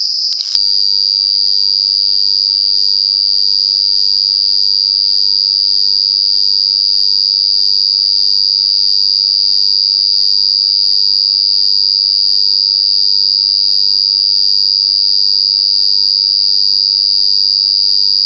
A male Tobacco cricket (Brachytrupes membranaceus) singing at the entrance to his burrow. The shape of the opening acts as an amplifier to his already very loud song.
At around 7:30 pm, just after it got really dark, the entire camp suddenly erupted in incredibly loud, buzzing racket when about a dozen cricket males started singing at the entrances to their burrows.
They also appear to be the loudest.
Its rather interesting… at a high frequency resolution there seems to be very distinct and constant frequencies from 4440 to 5129 Hz in approximately 100 Hz steps.
brachytrupes.wav